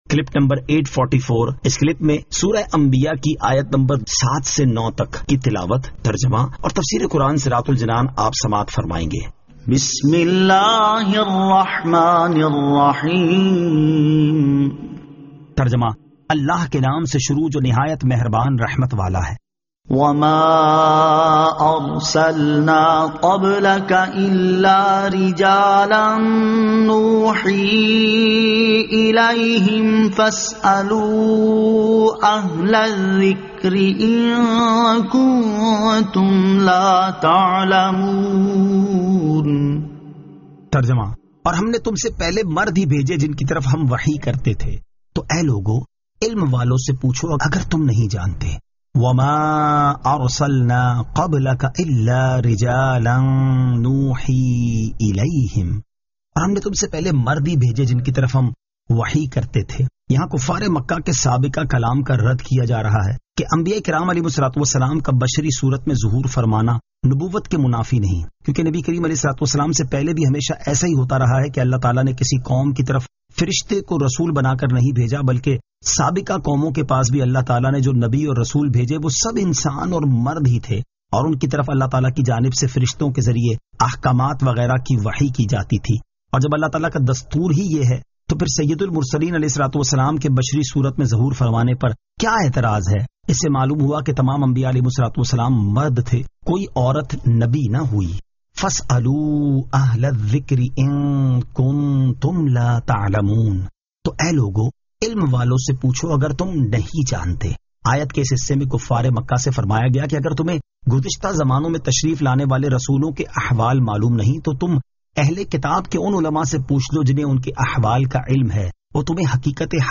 Surah Al-Anbiya 07 To 09 Tilawat , Tarjama , Tafseer